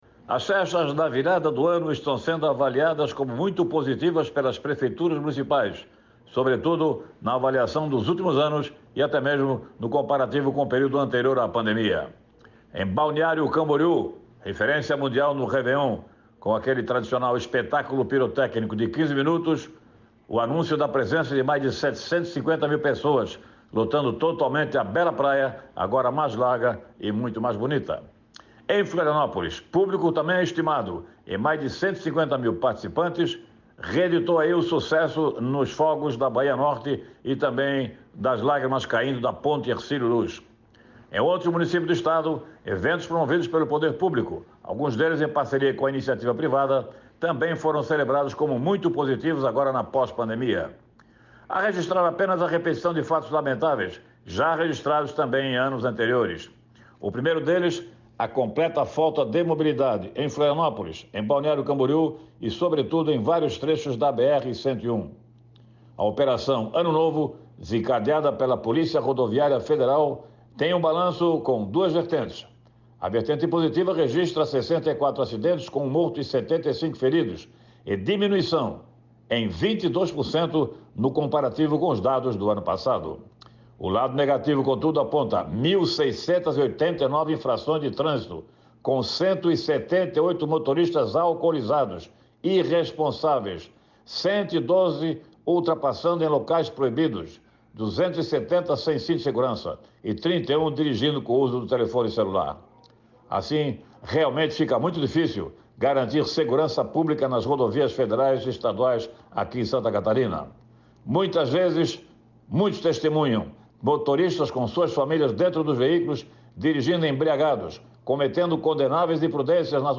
Confira o comentário desta terça-feira